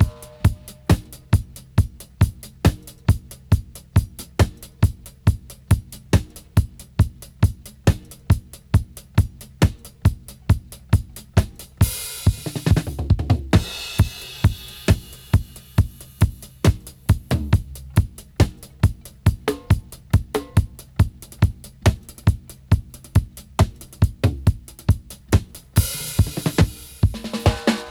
136-DRY-05.wav